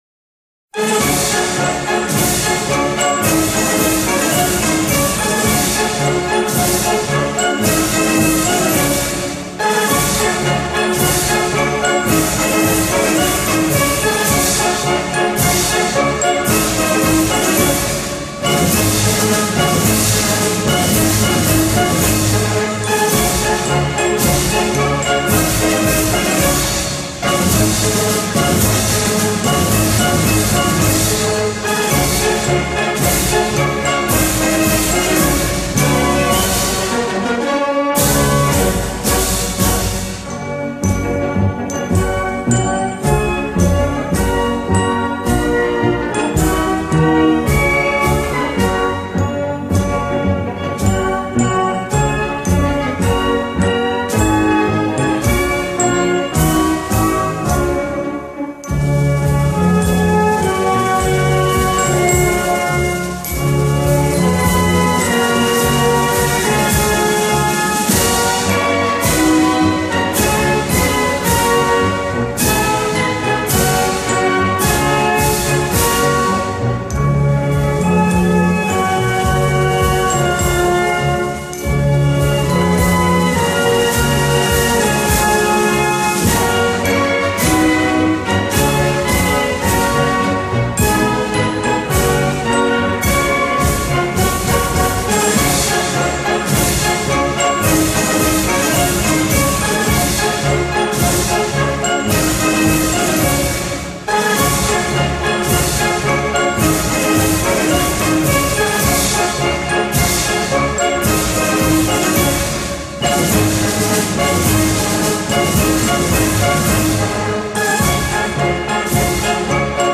Anthem
Etruarand_anthem.ogg